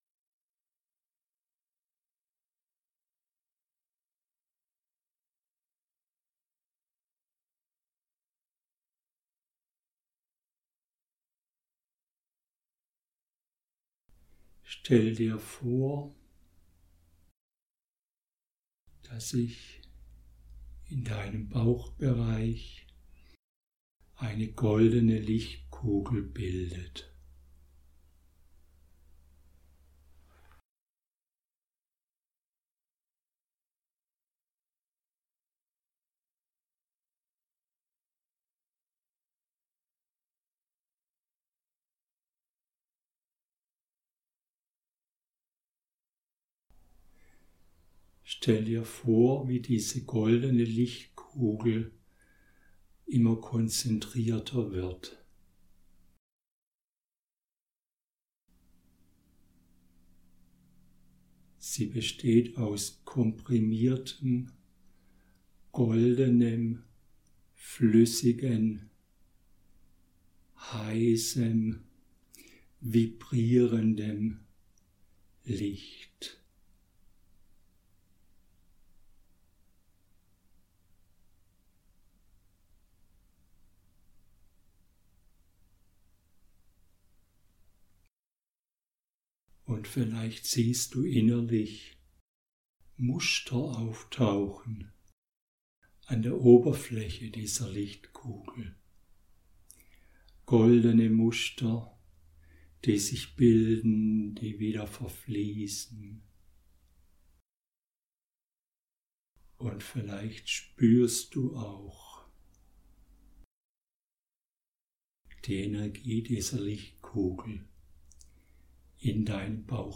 Die goldene Augen Meditation (meditative Übung)
Goldene-Augen-Meditation.mp3